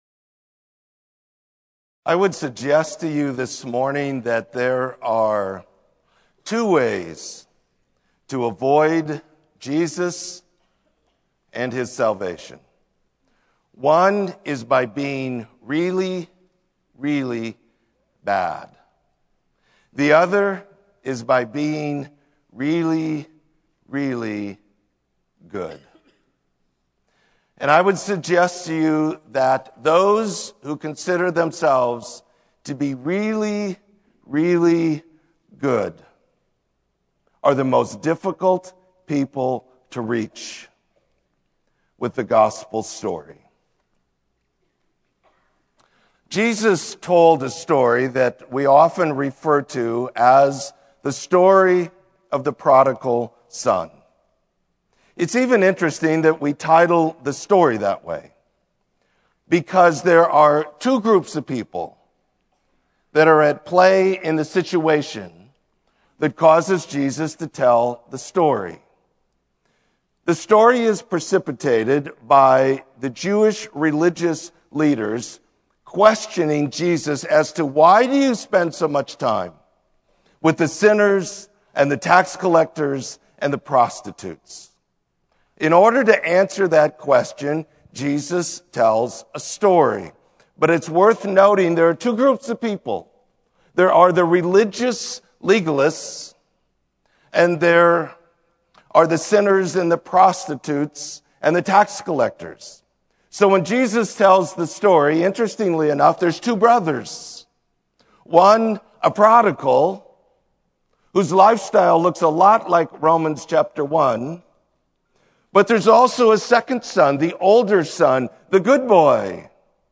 Sermon: Not by Works